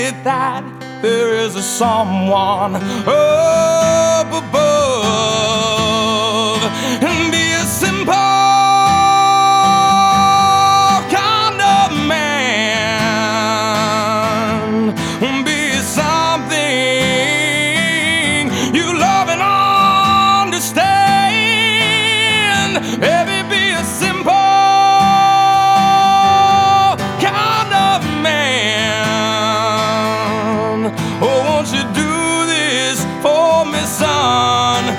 Жанр: Пост-хардкор / Хард-рок / Рок